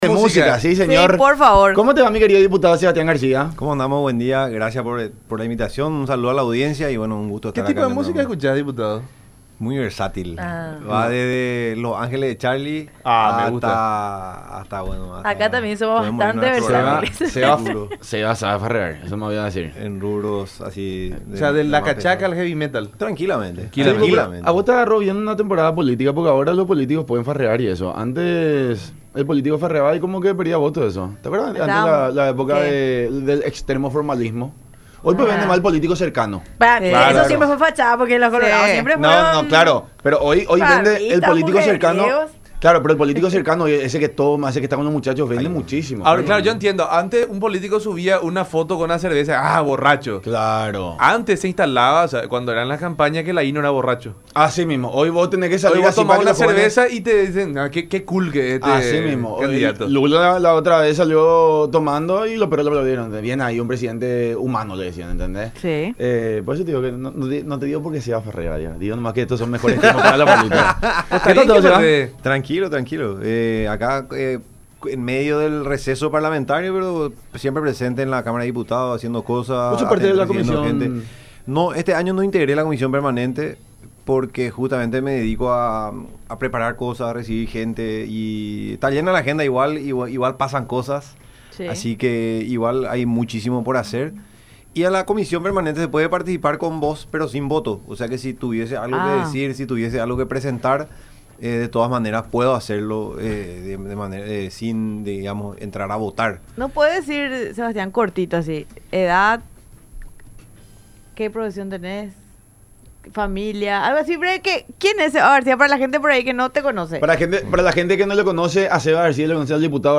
Sin embargo, en realidad lo que se quería establecer es un 10% de sobrecosto a los usuarios de plataformas digitales, el cual irá destinado a esa asociación”, dijo García durante su visita a los estudios de Unión TV y radio La Unión durante el programa La Unión Hace La Fuerza.